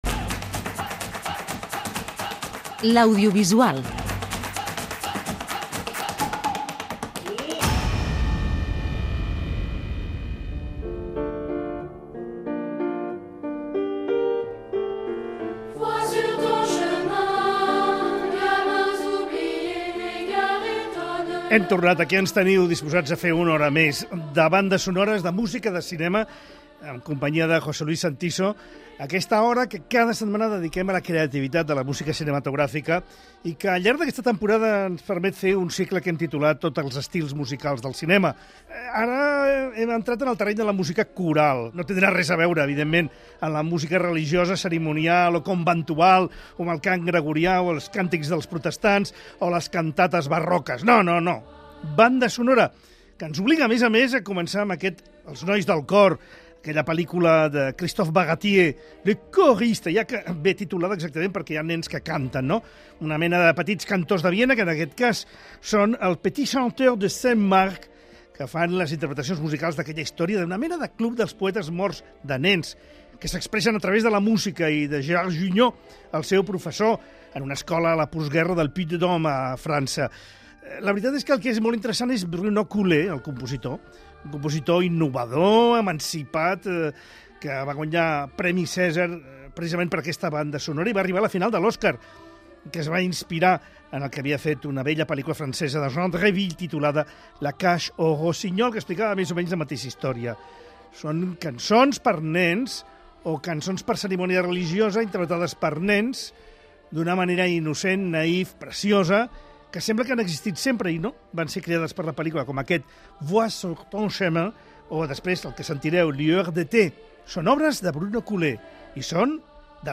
Podrem sentir diverses peces i petites meravelles que s'amaguen entre aquestes notes.